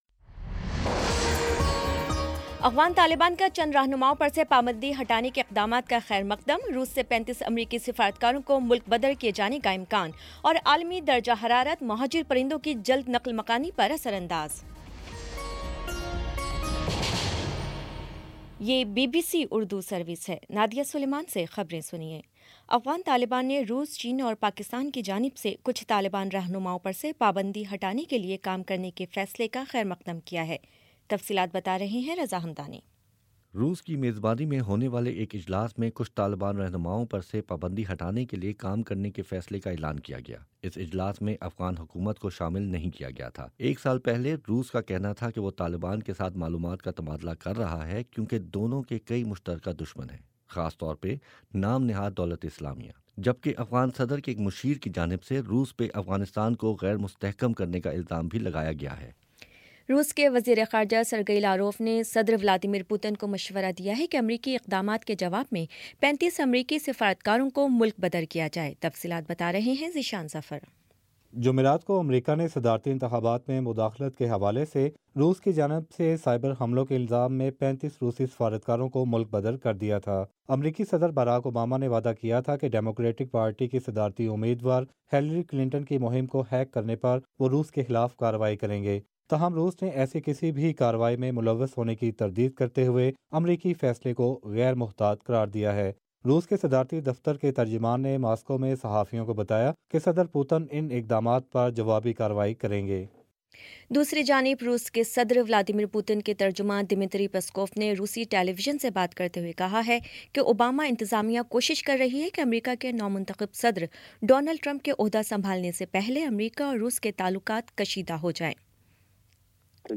دسمبر 30 : شام چھ بجے کا نیوز بُلیٹن